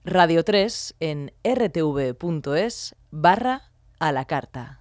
Identificació de Radio 3 a la carta